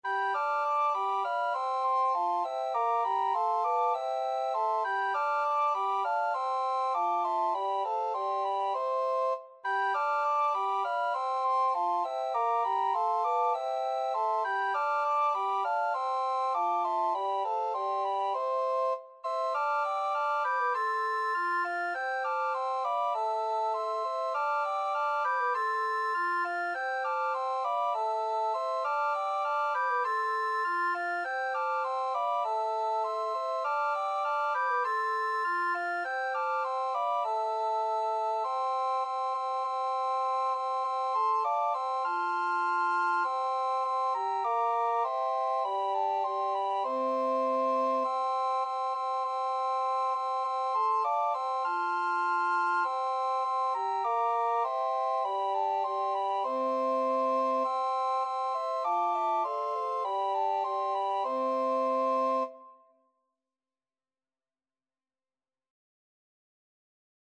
Free Sheet music for Recorder Quartet
Soprano Recorder 1Soprano Recorder 2Alto RecorderBass RecorderTenor Recorder
C major (Sounding Pitch) (View more C major Music for Recorder Quartet )
4/4 (View more 4/4 Music)
Recorder Quartet  (View more Intermediate Recorder Quartet Music)
Classical (View more Classical Recorder Quartet Music)
danserye_21_ronde_aliud_RECQ.mp3